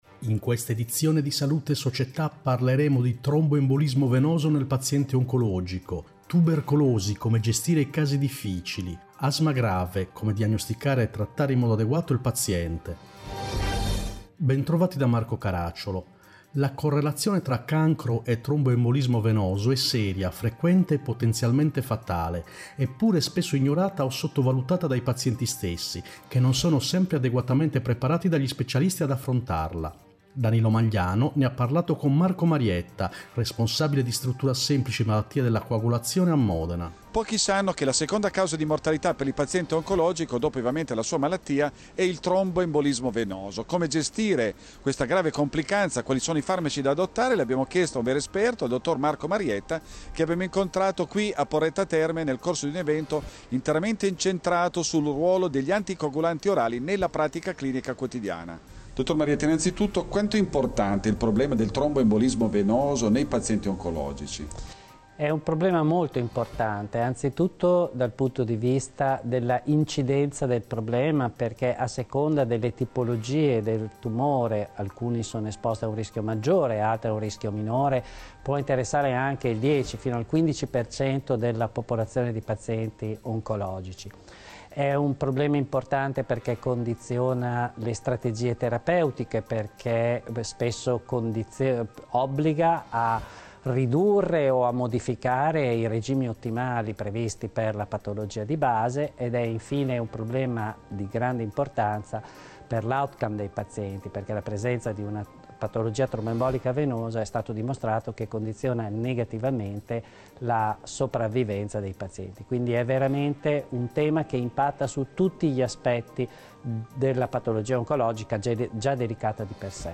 In questa edizione: Correlazione tra cancro e Tromboembolismo venoso Tubercolosi, come gestire i casi difficili Diagnosticare e trattare il paziente con asma grave Interviste